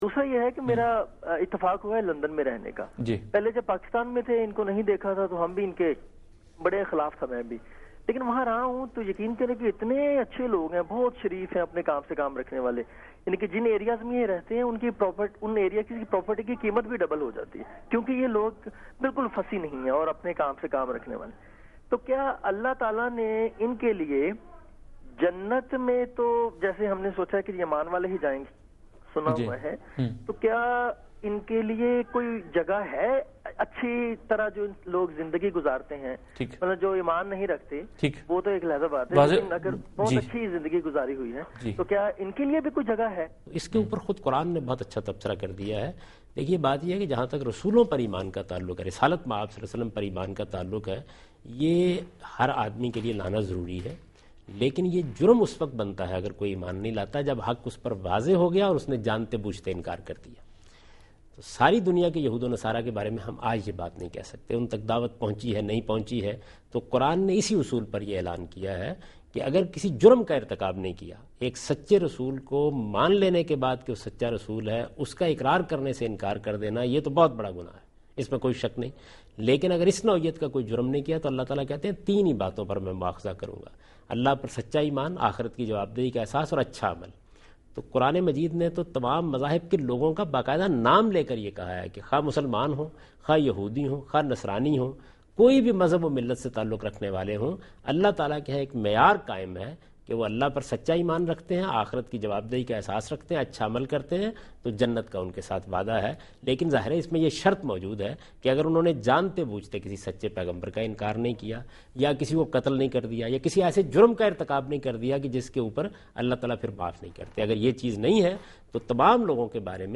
TV Programs